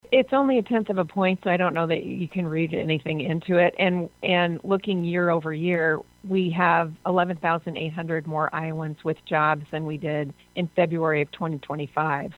THE LABOR FORCE PARTICIPATION RATE HAD BEEN MOVING UP SLOWLY, AND SHE SAYS THIS DROP IS NOT A MAJOR CONCERN.